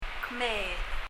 最後に「ゆっくり発音してみるから、しっかり聴いて」と発音していただきました
発音